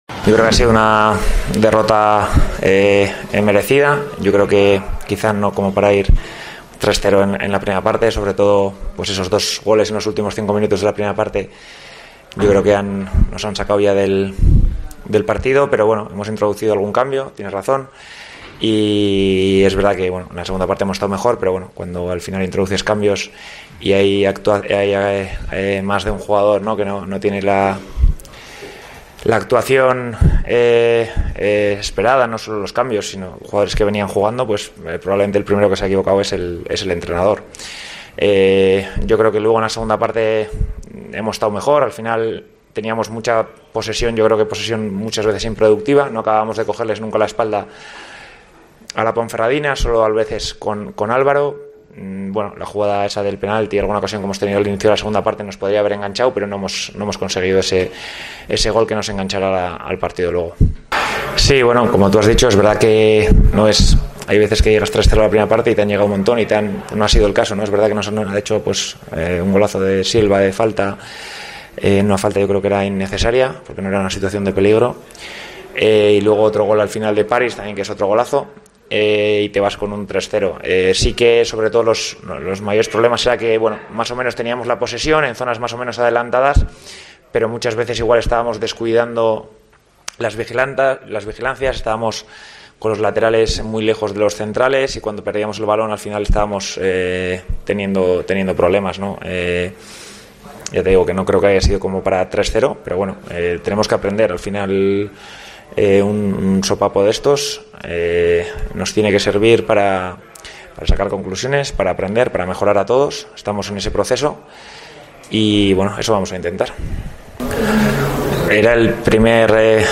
AUDIO: Escucha aquí el postpartido con declaraciones de Andoni Iraola, entrenador del Rayo Vallecaano, y Jon Pérez Bolo, míster de la Deportiva...